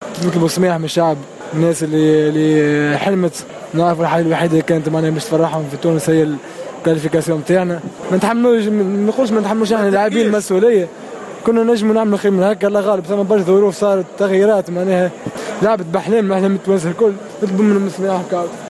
الفرجاني ساسي باكيا : نعتذر من الشعب التونسي
و أعرب ساسي ، بنبرة حزينة ، أن اللاعبين قد قدموا كل ما لديهم من أجل إسعاد الشعب التونسي لكن الأمور حالت دون ذلك .